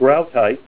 Help on Name Pronunciation: Name Pronunciation: Groutite
Say GROUTITE Help on Synonym: Synonym: ICSD 30659   PDF 24-713